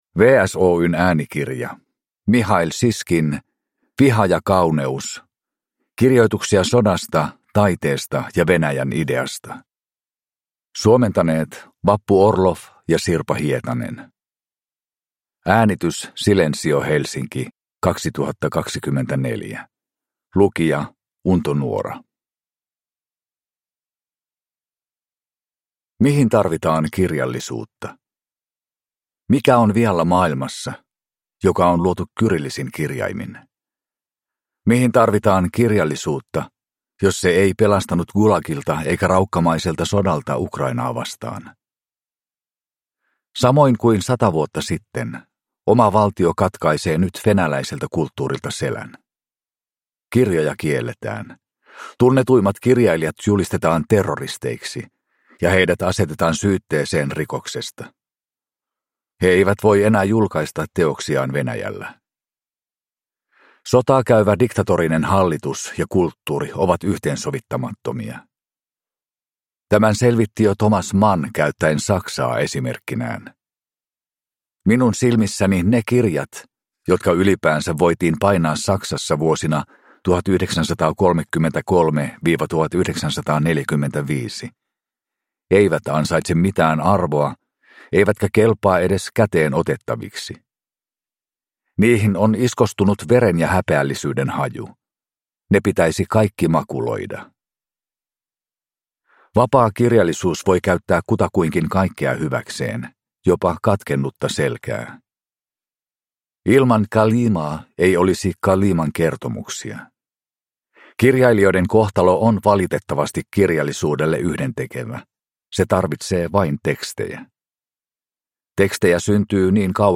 Viha ja kauneus (ljudbok) av Mihail Šiškin